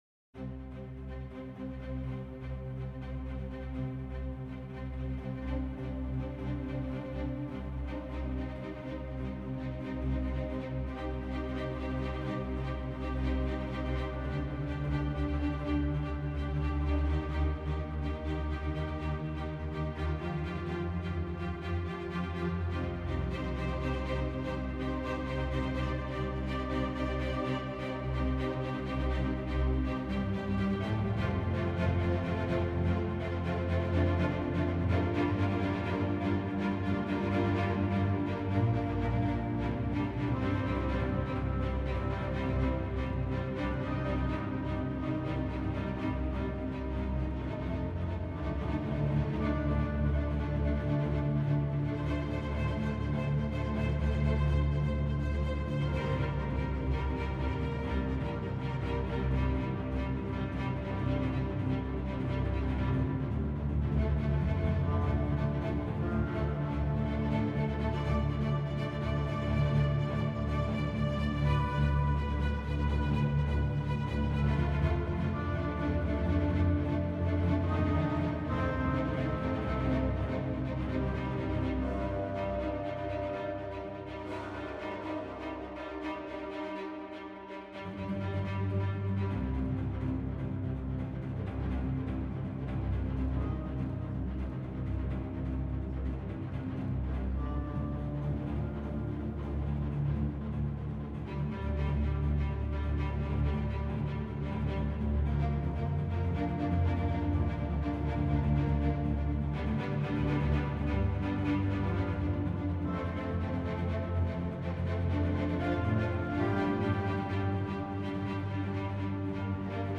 Musique de scène